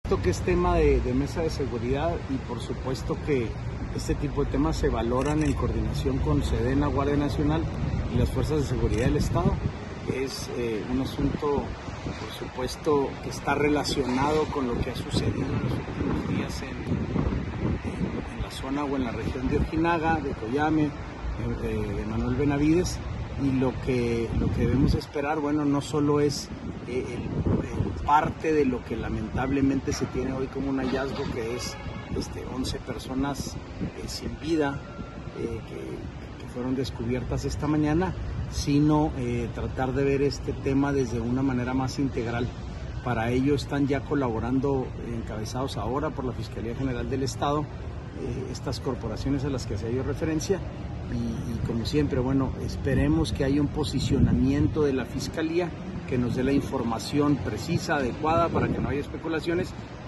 AUDIO: SANTIAGO DE LA PEÑA, SECRETARIO GENERAL DE GOBIERNO (SGG)